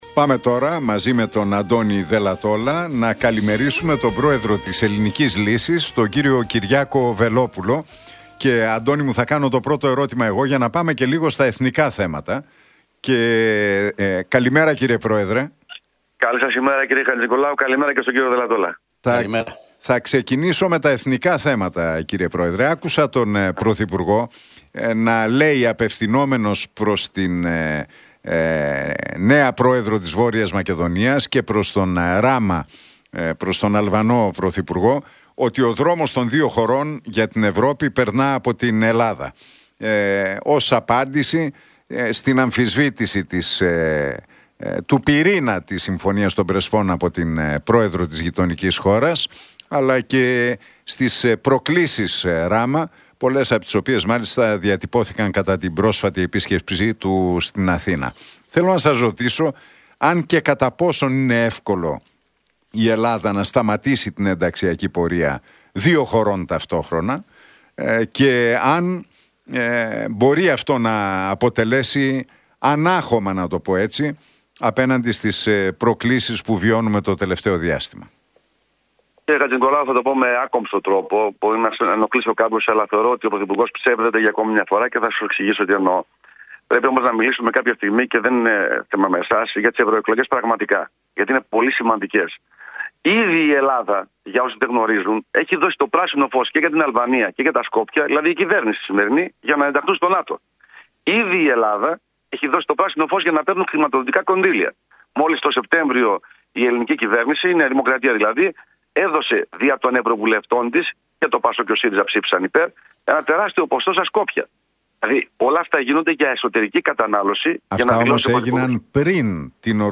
Ο πρόεδρος της Ελληνικής Λύσης, Κυριάκος Βελόπουλος, μίλησε στους Νίκο Χατζηνικολάου